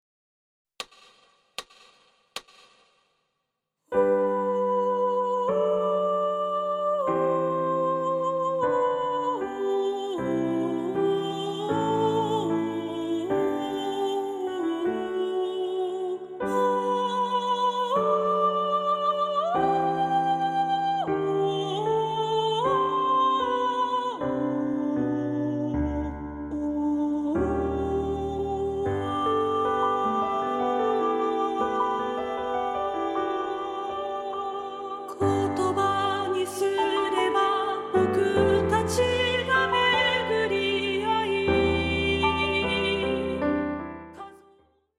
合唱パート練習CD
混声3部合唱／伴奏：ピアノ